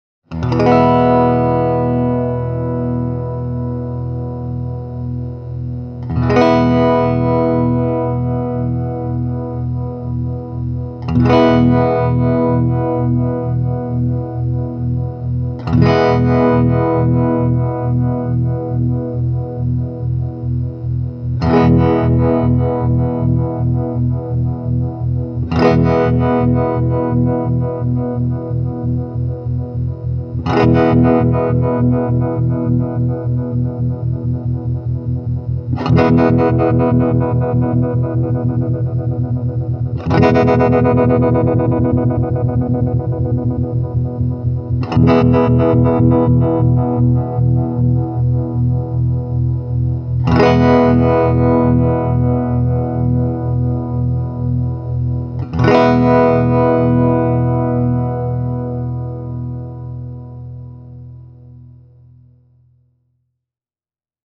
bluetone-harmonic-tremolo.mp3